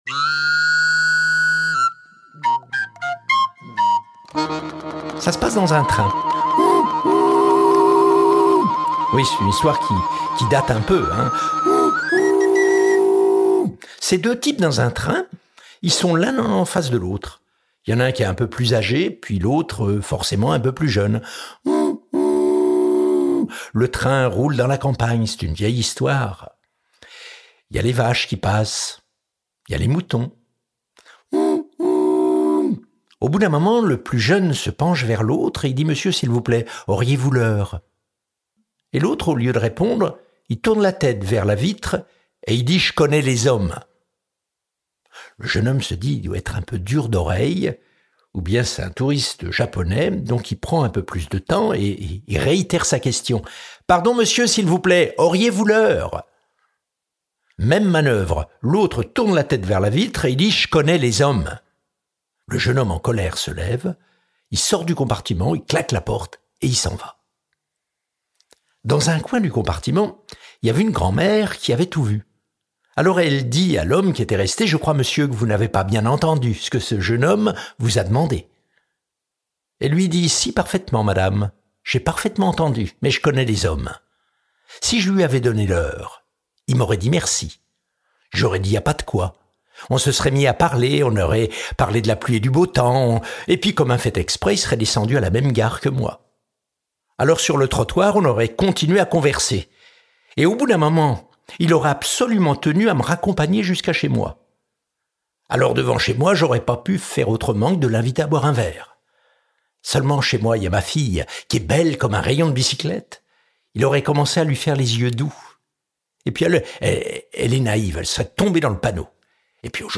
2011-11-24 Réalisé lors de la vingtième édition du Festival du Conte des Alpes-Maritimes, ce disque réunit des artistes qui en écrivirent les plus belles pages au fil des ans. Rencontres de traditions et de cultures multiples, il est l’expression de toutes ces mémoires francophones.